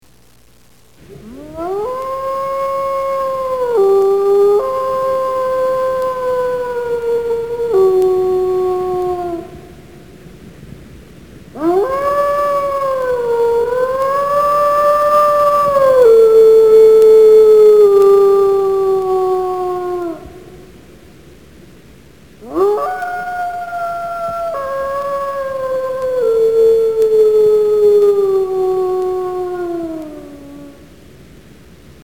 Звуки воя волка
Волк воет на луну среди деревьев